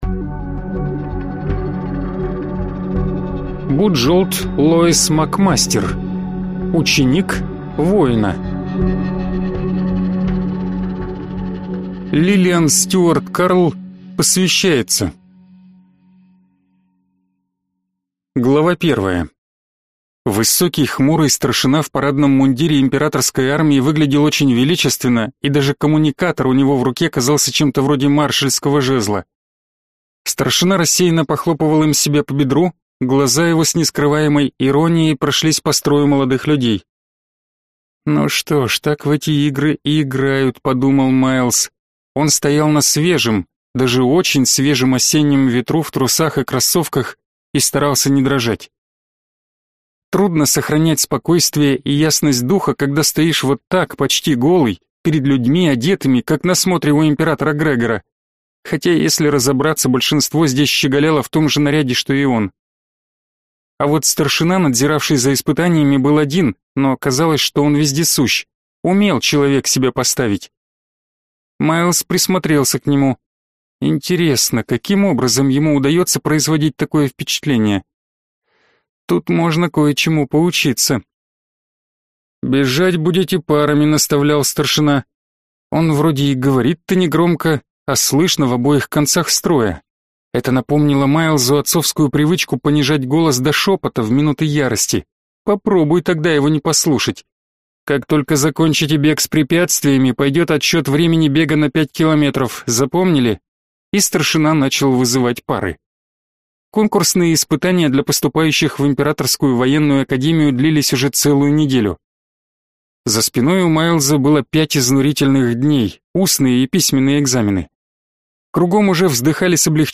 Аудиокнига Ученик воина | Библиотека аудиокниг